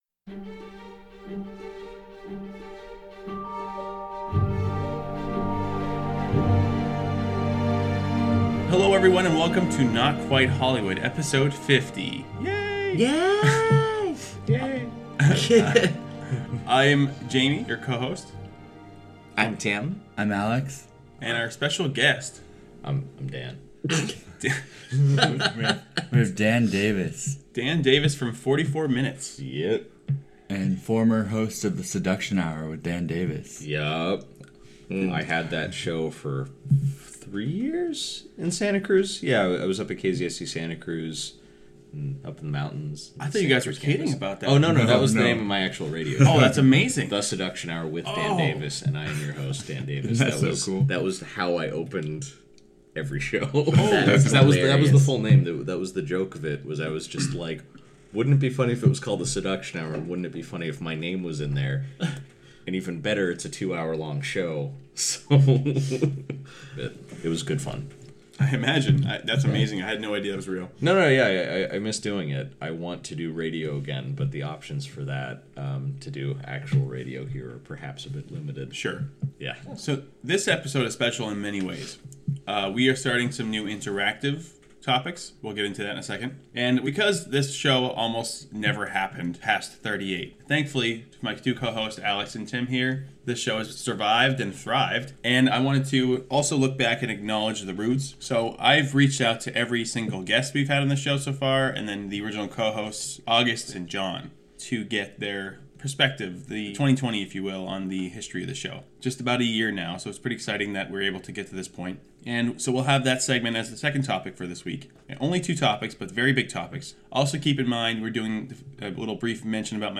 [I apologize for the audio issues with this one]